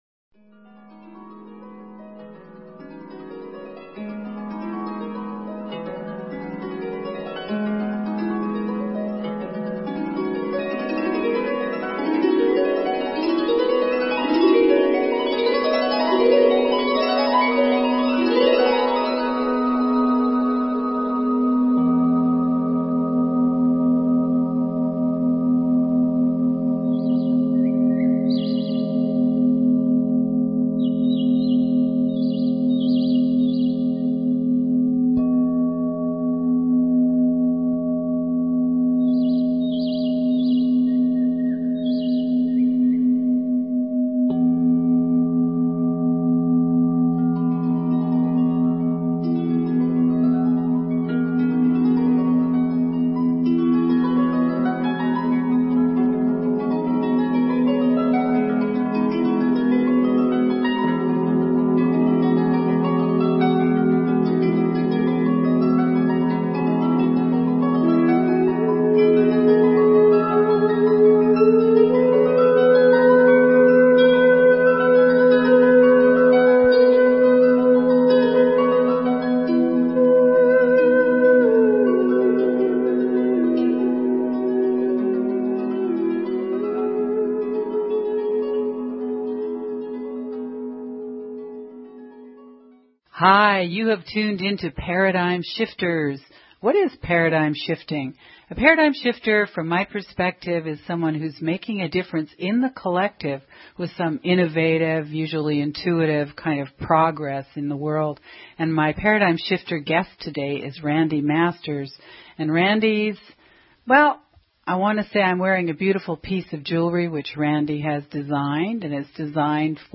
Talk Show Episode, Audio Podcast, Paradigm_Shifters and Courtesy of BBS Radio on , show guests , about , categorized as
Tune in and hear chimes that relay the sound of rainbows, or of hydrogen, our deepest root power.